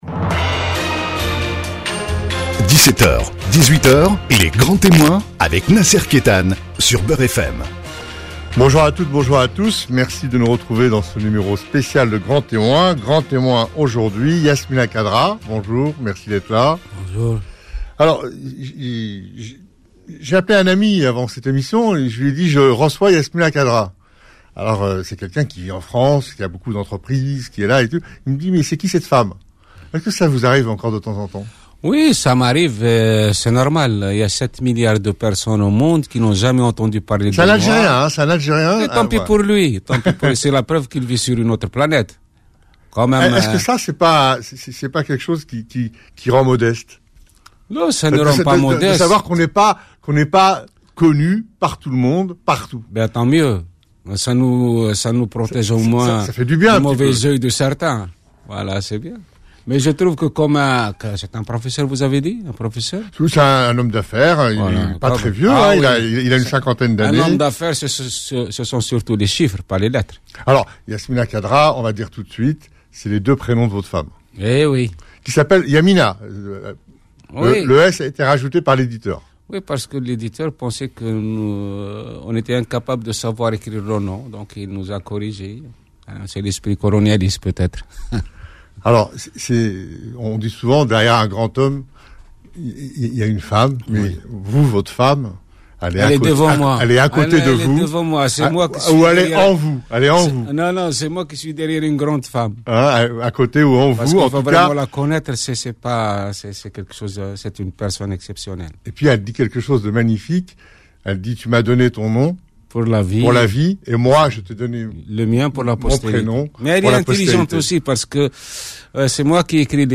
Yasmina Khadra Face à face avec le grand témoin de l'actualité de la semaine !